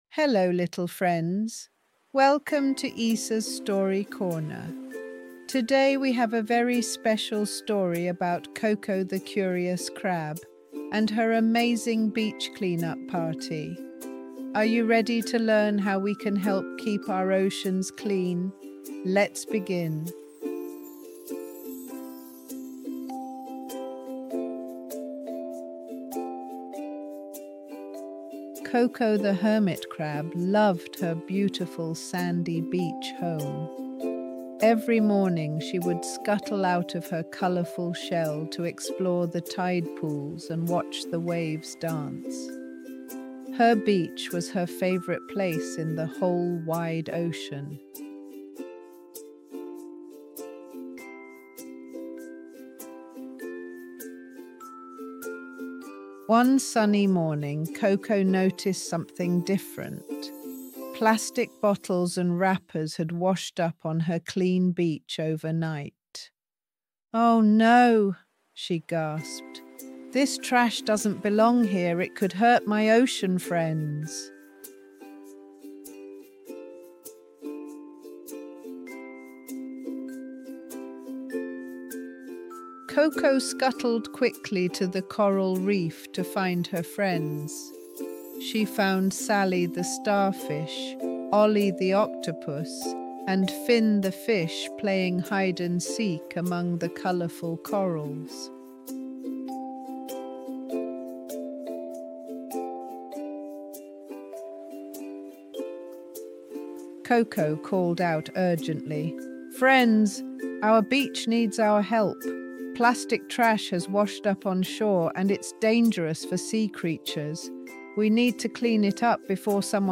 Cuento en Audio